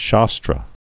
(shästrə)